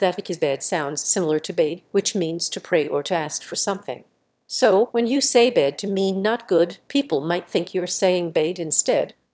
pronounciation1_AirportAnnouncements_1.wav